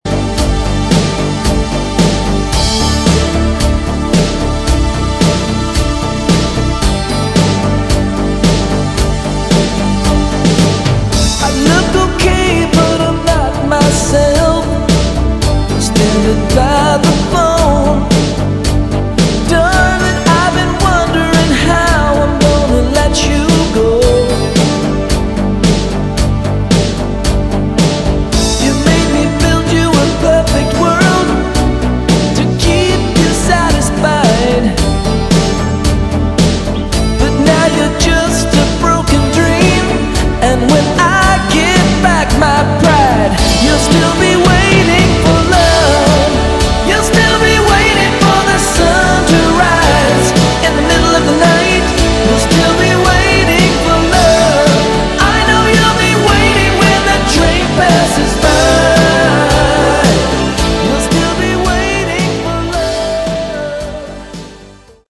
Original Demo